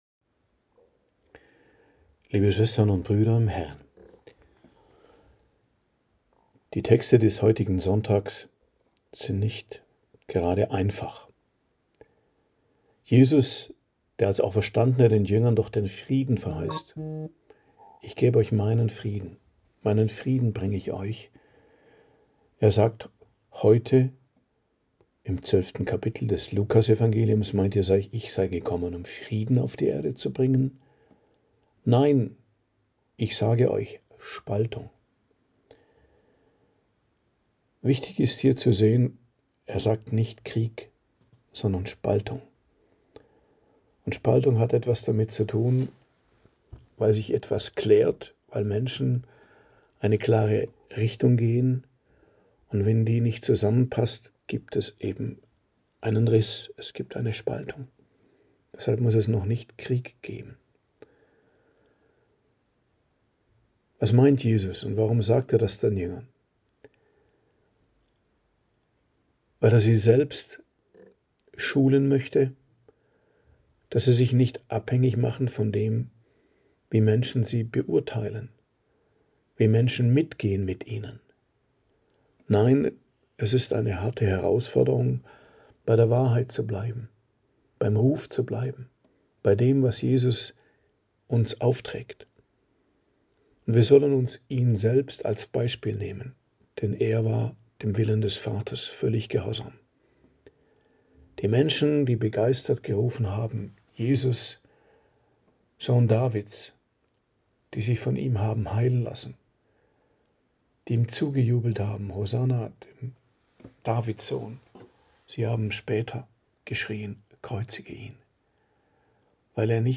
Predigt zum 20. Sonntag i.J., 17.08.2025 ~ Geistliches Zentrum Kloster Heiligkreuztal Podcast